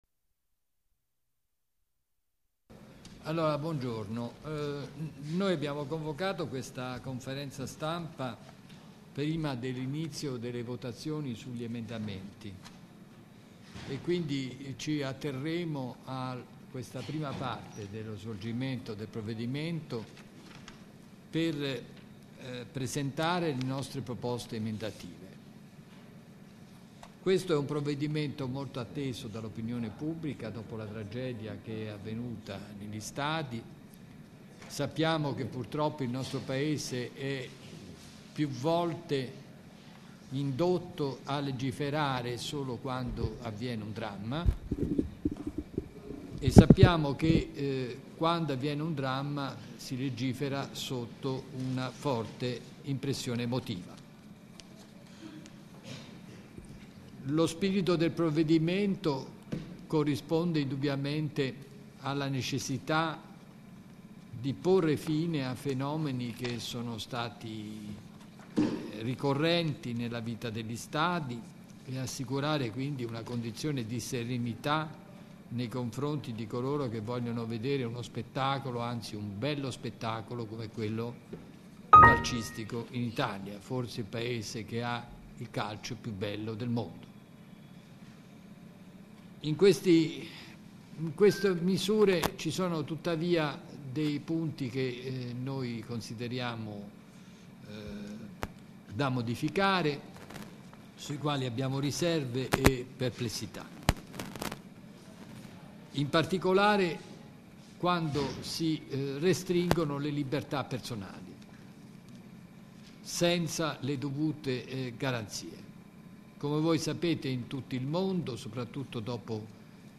Al riguardo, ieri sono stato invitato, in qualità di "esperto" a partecipare alla conferenza stampa della Rosa nel Pugno sul decreto Amato, puntualmente approvato dalla Camera dei Deputati, sia pure con qualche emendamento significativo che illustrerò in seguito, visto che tanto entro il 2 aprile dovrà essere convertito in legge dopo l'approvazione del Senato. Per chi può interessare, qui c'è il file audio MP3 (tratto dal sito di Radio Radicale ) della conferenza stampa.